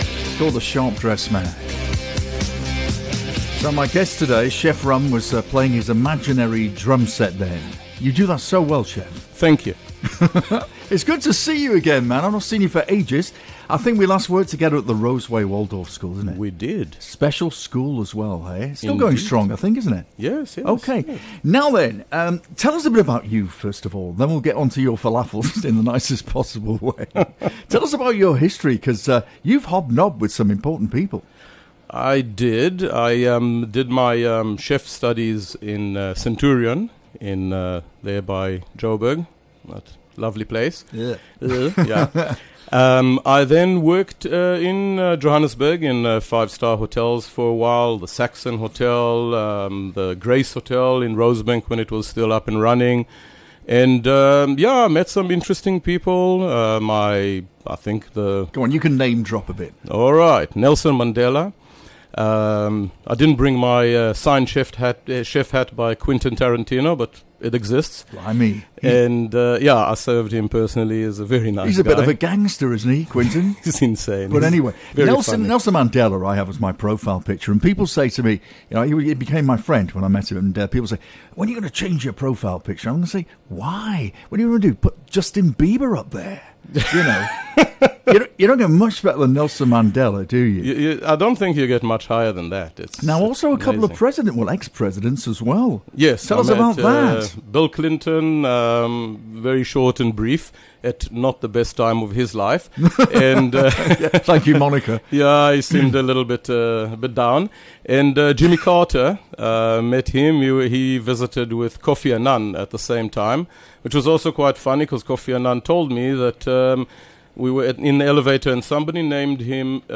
Broadcasting live from the heart of Ballito, The Afternoon Show serves up a curated mix of contemporary music and hits from across the decades, alongside interviews with tastemakers and influencer…